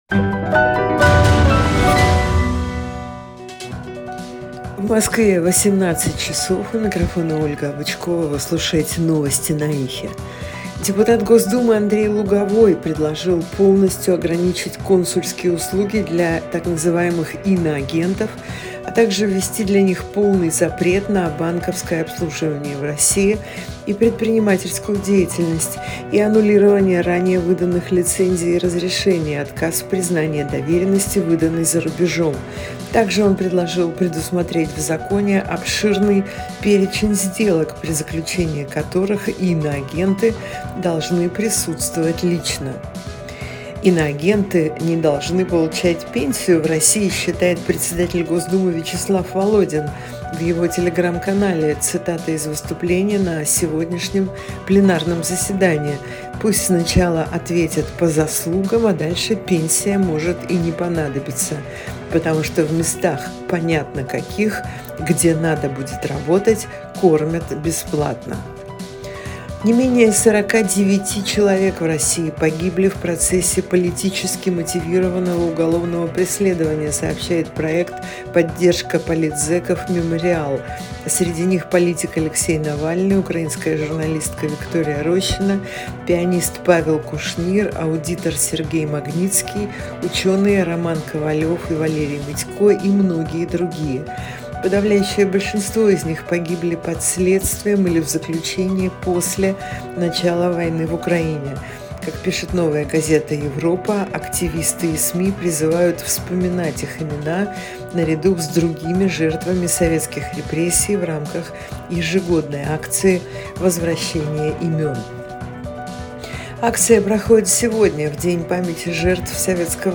Слушайте свежий выпуск новостей «Эха»
Новости 18:00